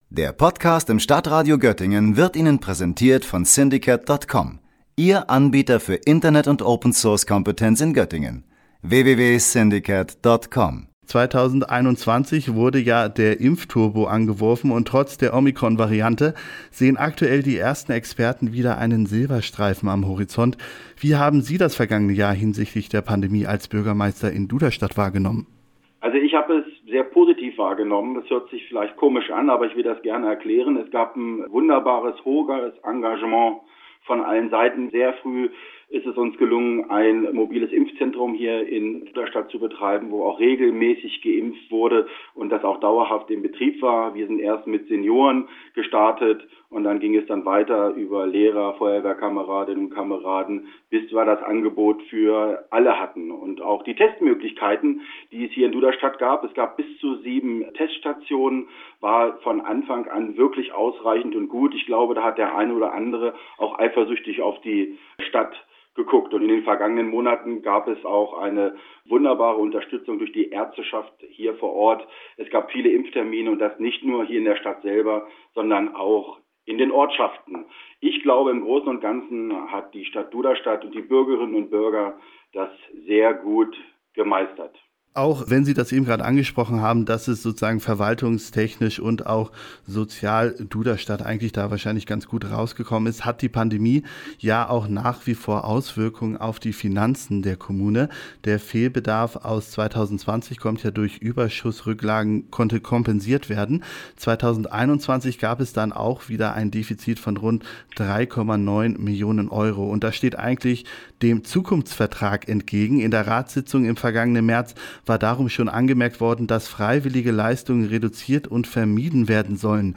Beiträge > Haushaltsdefizit, Innenstadtentwicklung und Baumaßnahmen – Duderstadts Bürgermeister Thorsten Feike im Interview - StadtRadio Göttingen